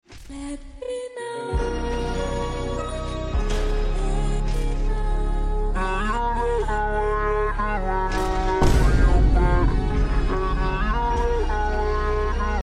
Man Face Ahh Jumpscare 💀 Sound Effects Free Download